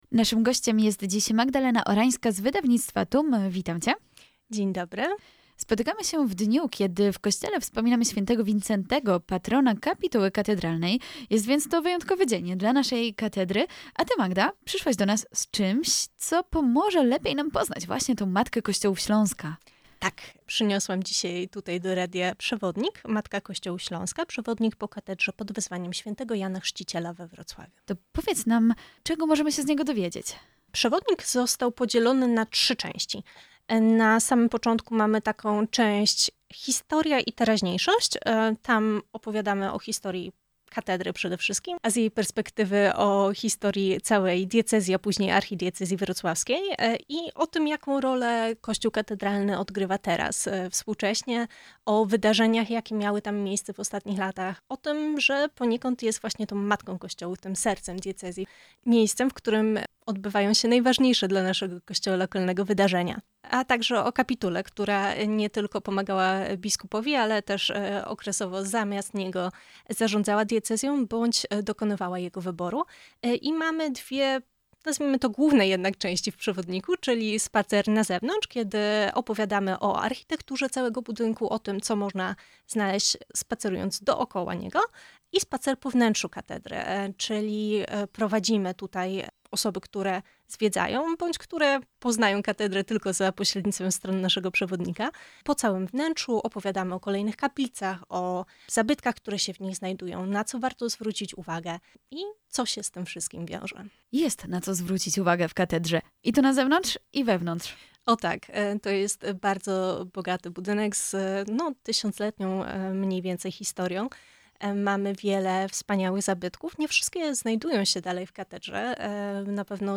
O tajnikach Matki kościołów Śląska opowiadała na naszej antenie